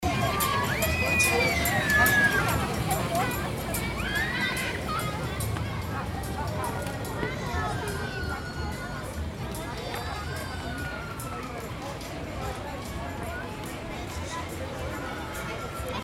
Rummel Menschen Schreie.mp3